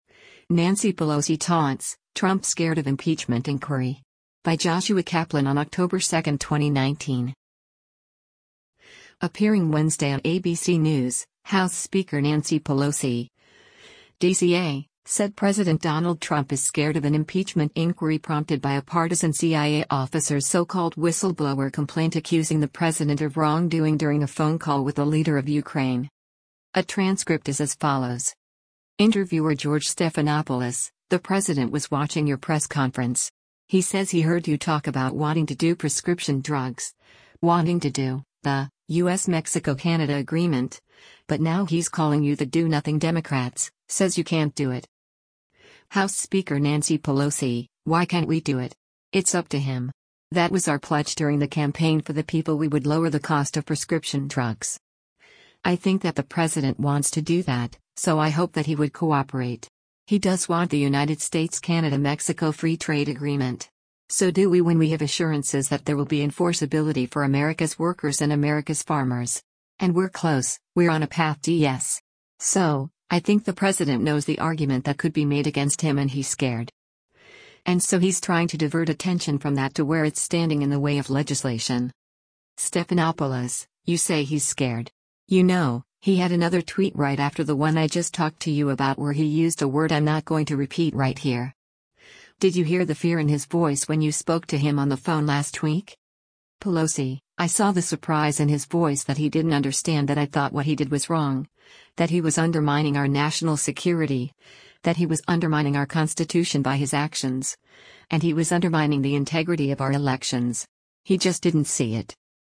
Appearing Wednesday on ABC News, House Speaker Nancy Pelosi (D-CA) said President Donald Trump is “scared” of an impeachment inquiry prompted by a partisan CIA officer’s so-called “whistleblower” complaint accusing the president of wrongdoing during a phone call with the leader of Ukraine.